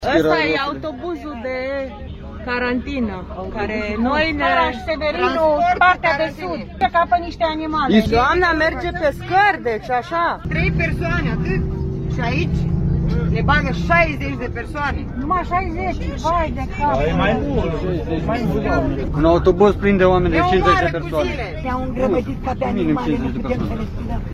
Prefectul județului Arad, Gheorghe Stoian a promis că se vor lua măsuri să nu se mai repete astfel de situații: